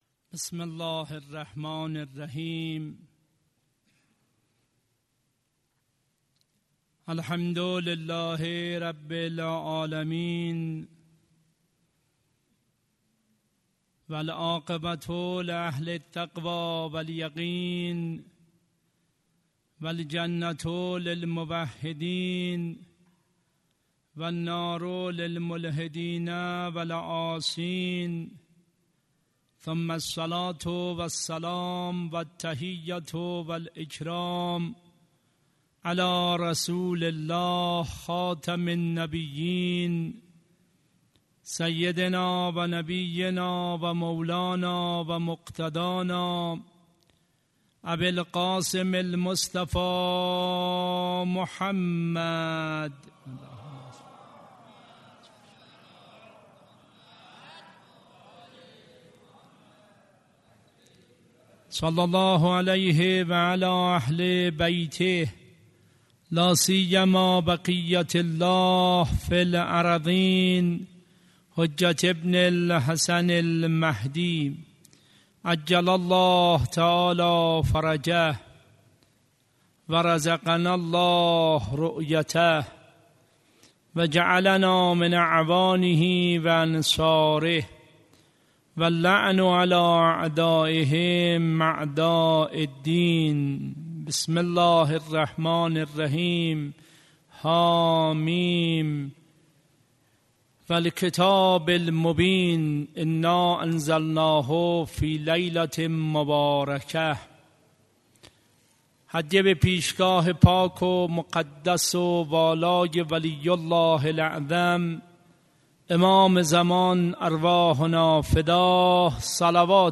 سخنرانی حضرت آیت الله حاج سید محمد جواد آیت اللهی در هیئت انصار ولایت یزد شب ۲۳ماه رمضان